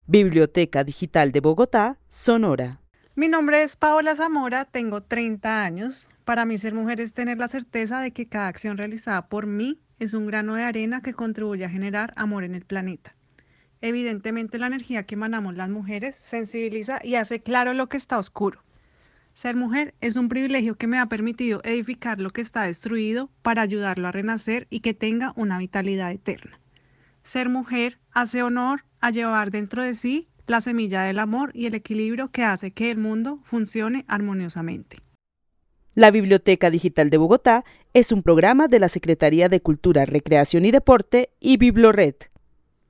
Narración oral sobre lo que significa ser mujer.